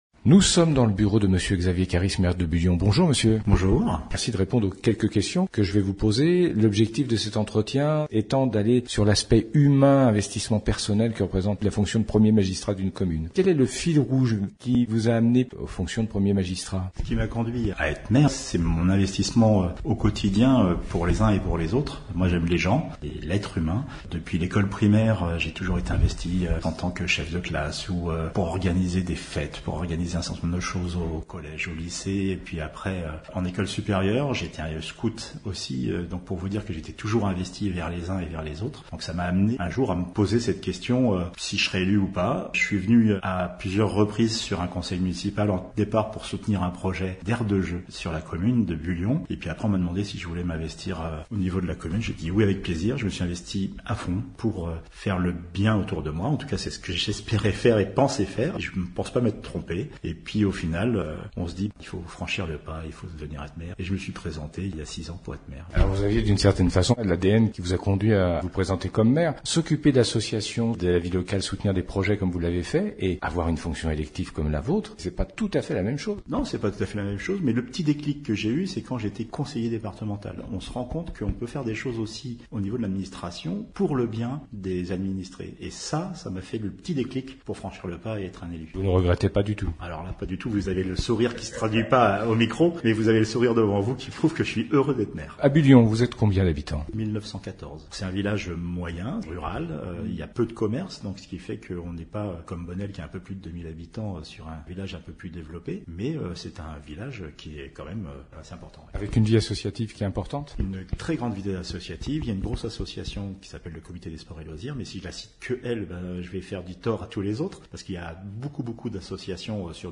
Interview de Monsieur Xavier Caris Maire de Bullion - Radio RVE
L'interview des maires Interview de Monsieur Xavier Caris Maire de Bullion Play Episode Pause Episode Mute/Unmute Episode Rewind 10 Seconds 1x Fast Forward 30 seconds 00:00 / 10:33 Subscribe Share RSS Feed Share Link Embed